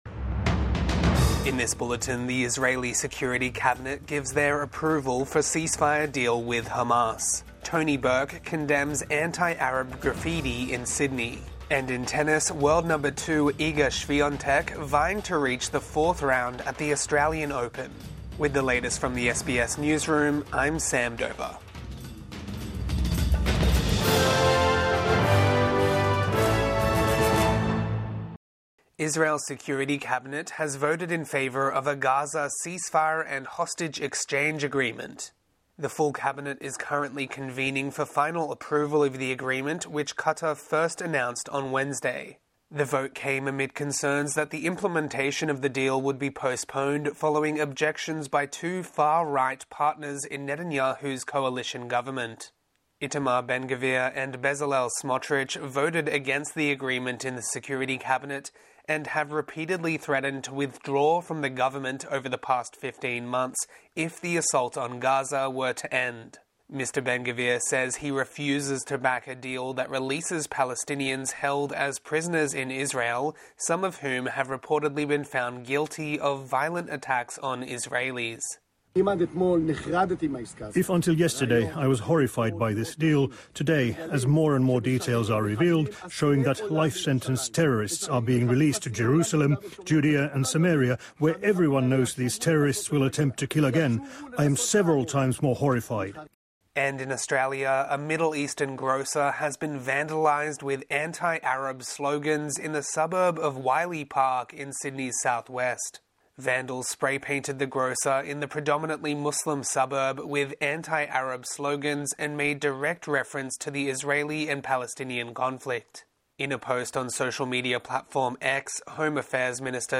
Morning News Bulletin 18 January 2025